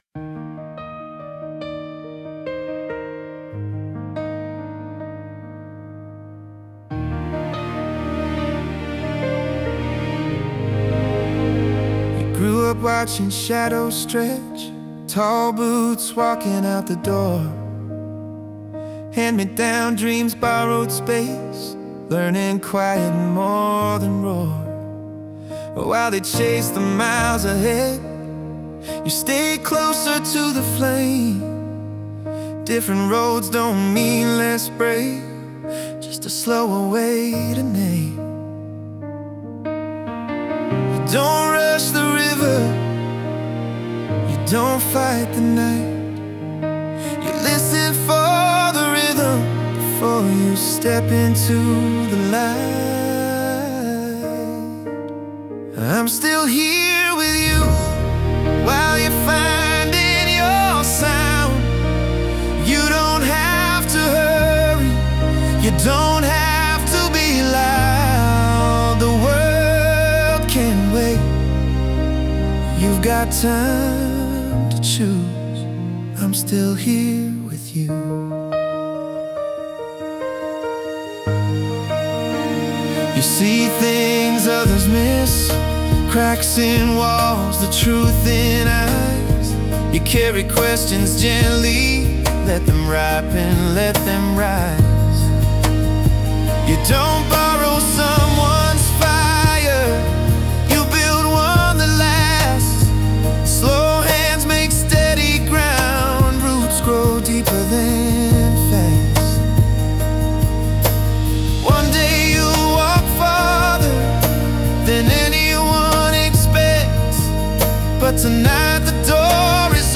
Explore Our Acoustic Tracks